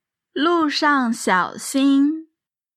Lù shàng xiǎo xīn
ルー シャン シァォ シン